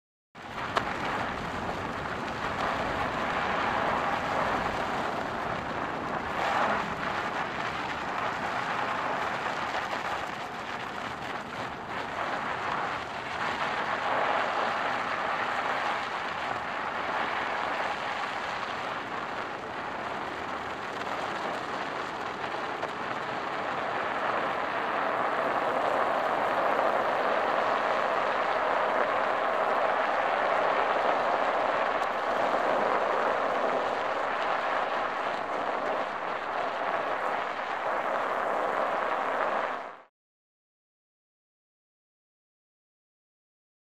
Tires Snow | Sneak On The Lot